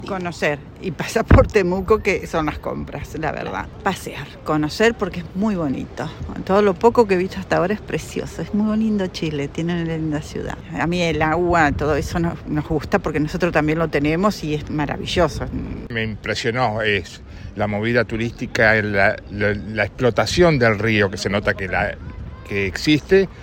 Con su acento característico, familias argentinas recorren la feria fluvial, el mercado, la costanera y las playas de la costa de Valdivia.